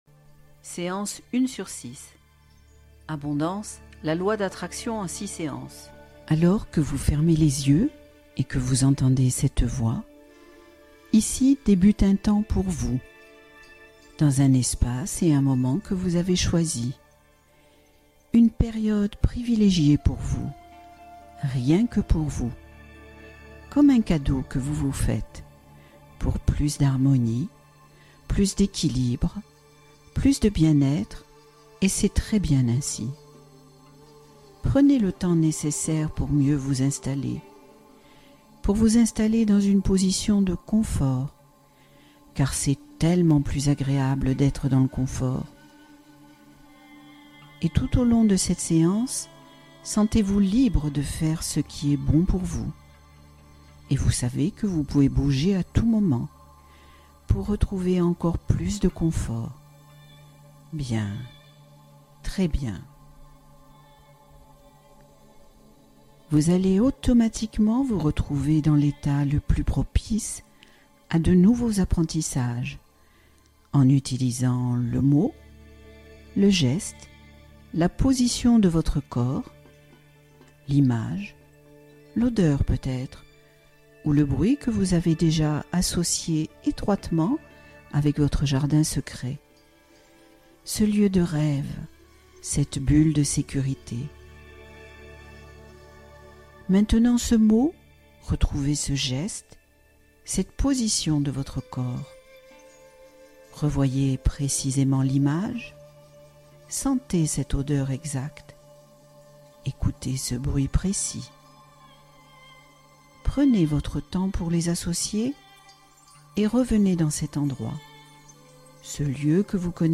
Confiance en Soi : Séance d'hypnose pour renforcer son image personnelle